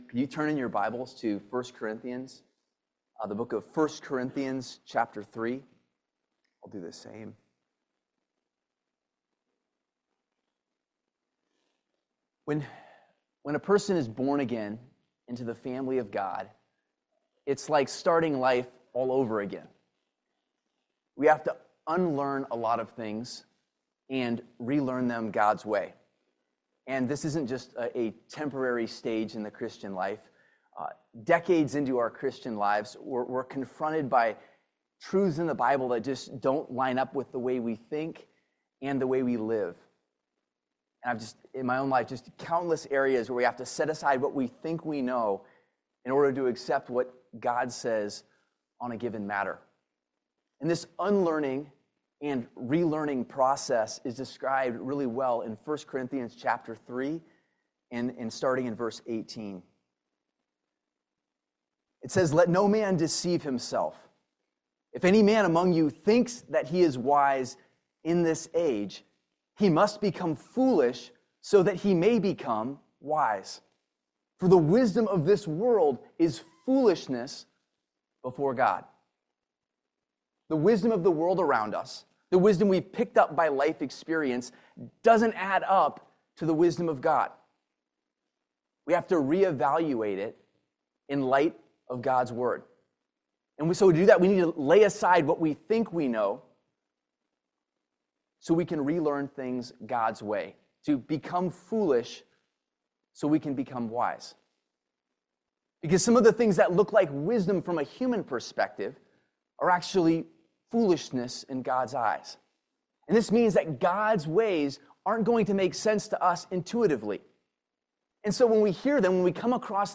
Ephesians 5:22-34 Service Type: Family Bible Hour Apply the truths of Christ’s order in marriage.